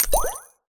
potion_flask_mana_collect_01.wav